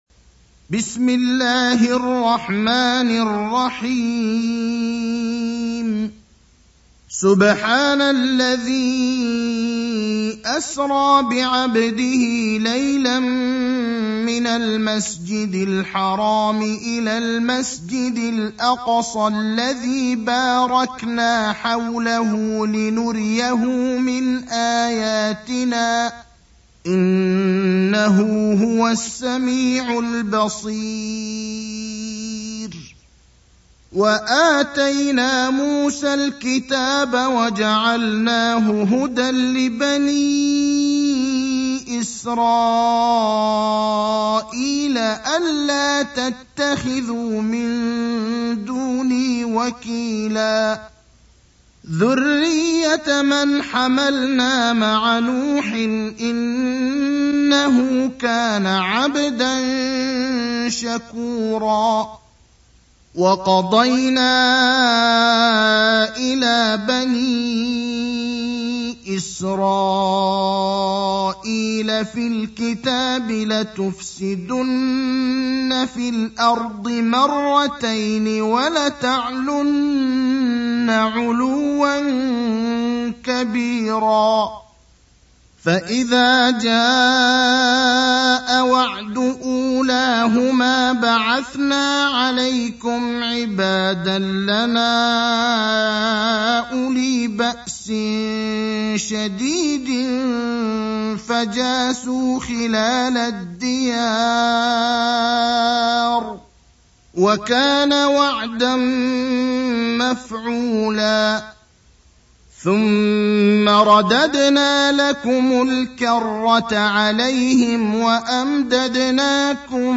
المكان: المسجد النبوي الشيخ: فضيلة الشيخ إبراهيم الأخضر فضيلة الشيخ إبراهيم الأخضر الإسراء